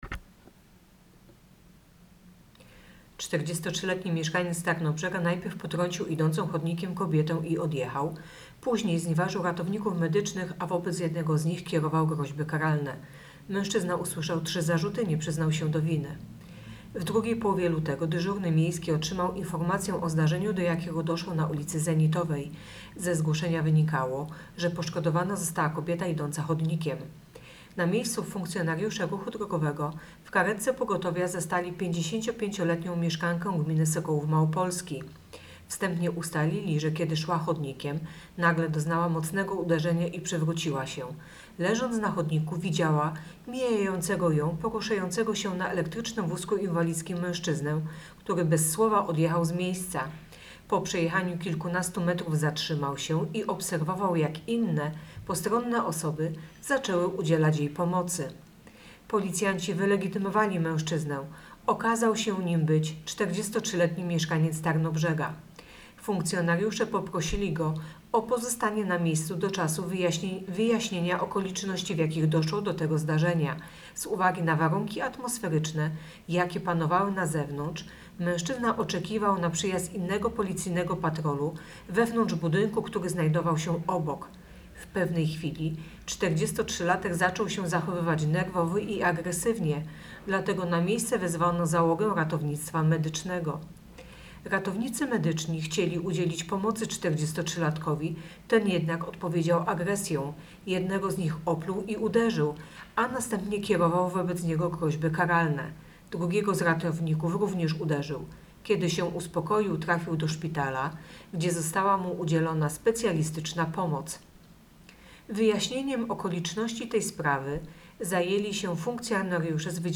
Opis nagrania: Nagranie informacji pt. Celowo wjechał w pieszą i znieważył ratowników medycznych. Trzy zarzuty dla 43-latka.